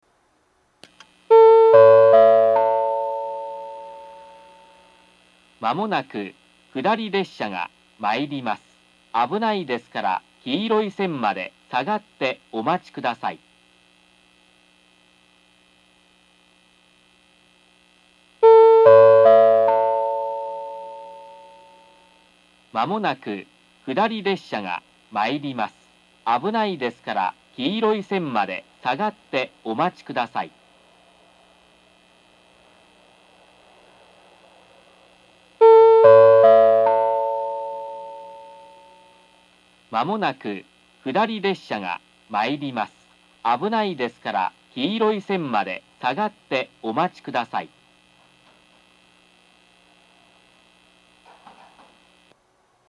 １番線下り接近放送